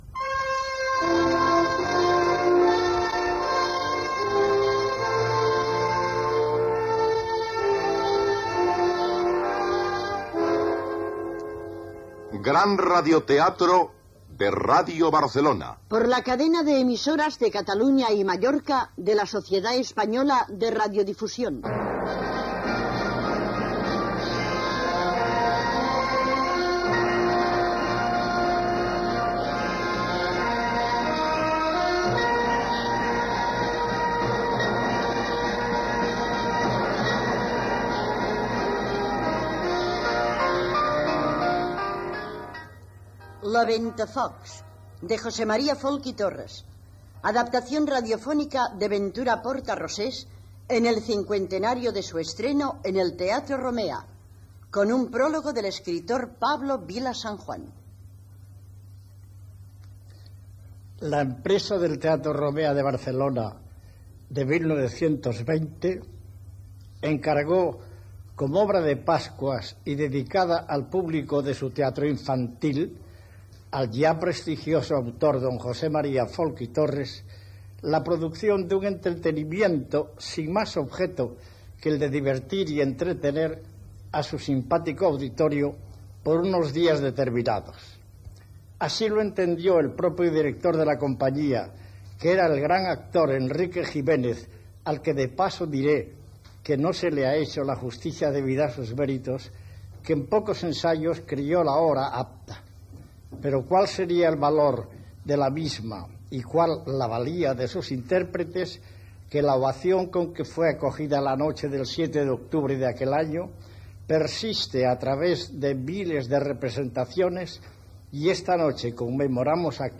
Gènere radiofònic Ficció